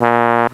Index of /m8-backup/M8/Samples/FAIRLIGHT CMI IIX/BRASS1